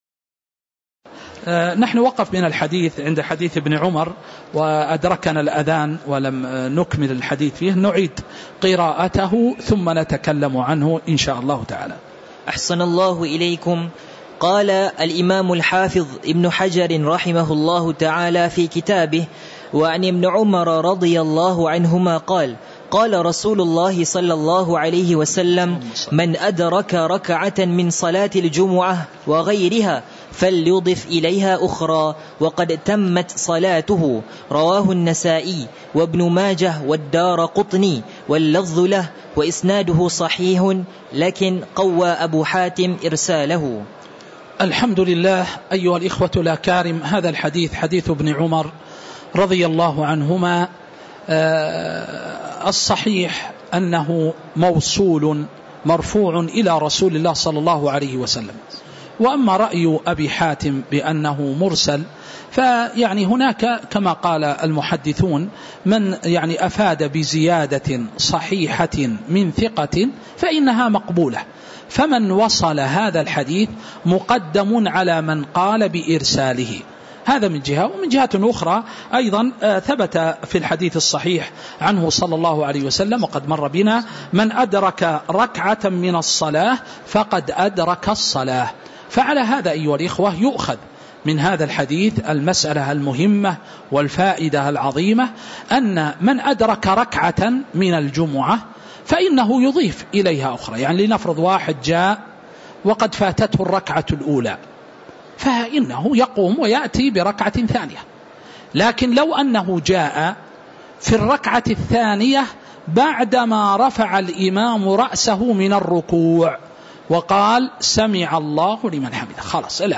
تاريخ النشر ١١ رجب ١٤٤٥ هـ المكان: المسجد النبوي الشيخ